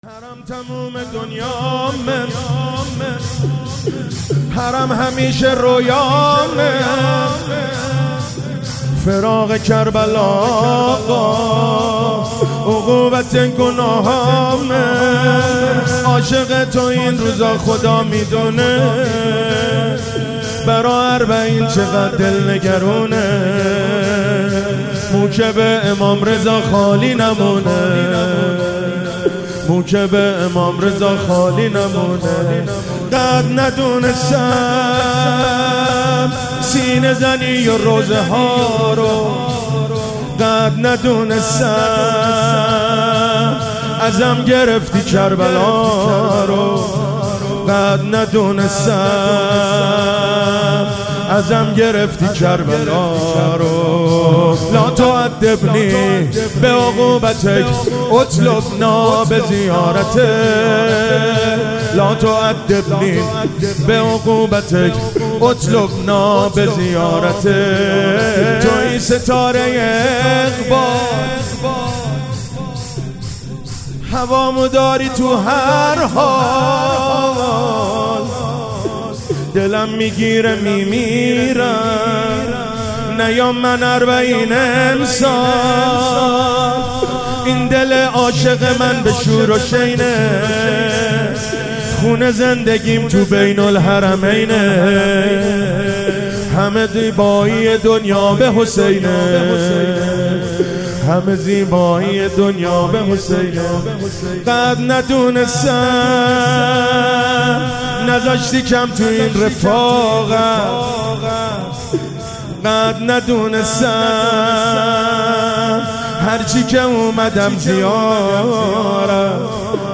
موکب الشهدا ساوجبلاغ